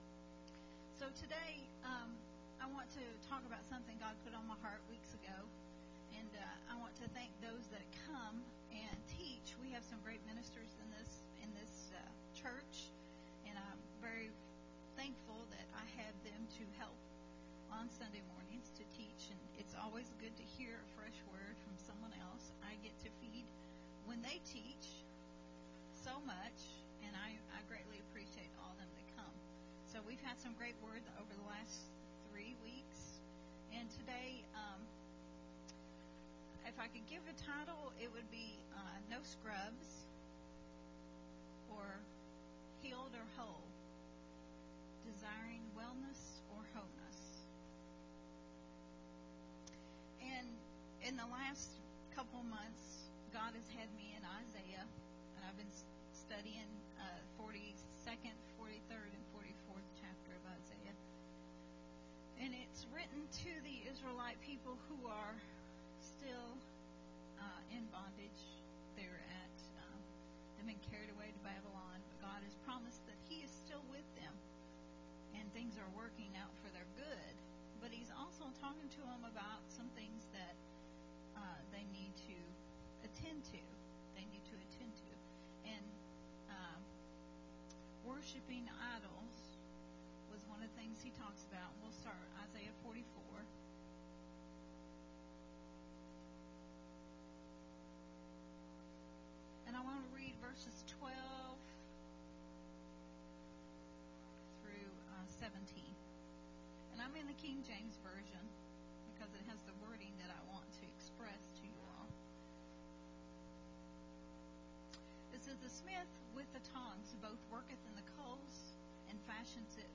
a Sunday Morning Refreshing teaching
recorded at Unity Worship Center